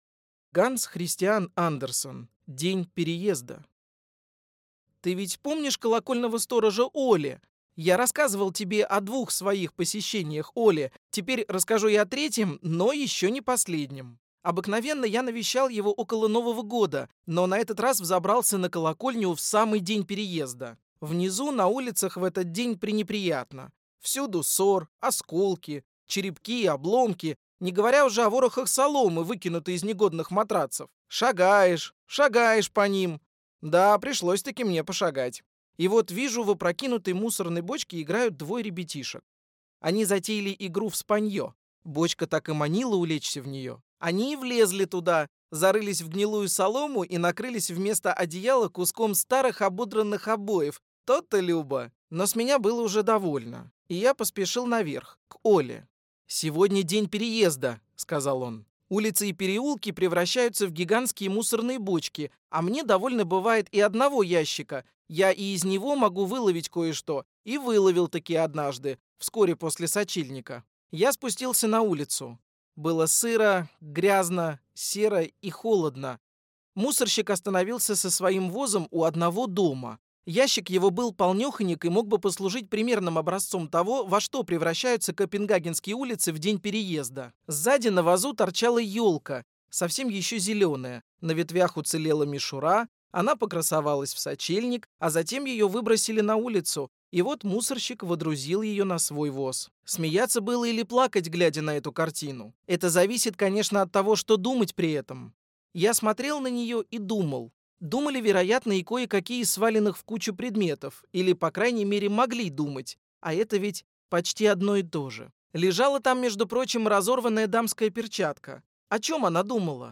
Аудиокнига День переезда | Библиотека аудиокниг